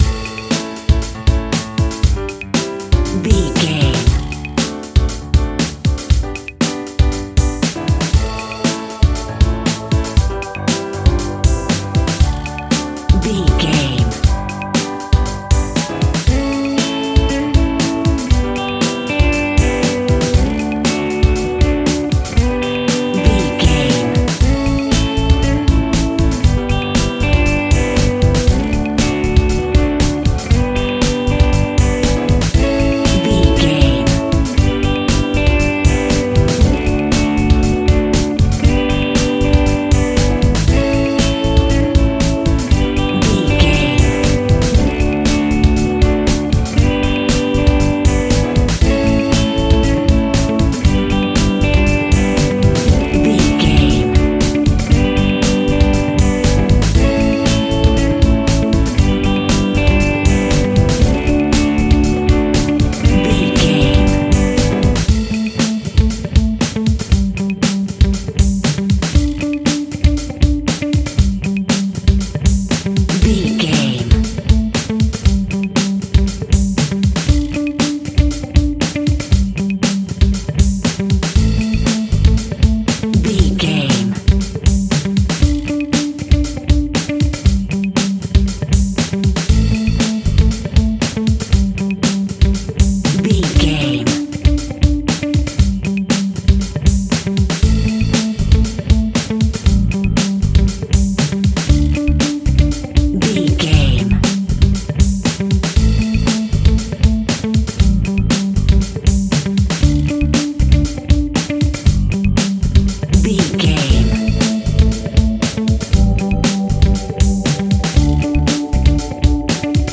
Ionian/Major
pop
pop rock
indie pop
fun
energetic
uplifting
cheesy
drums
bass guitar
electric guitar
synthesizers